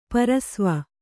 ♪ parasva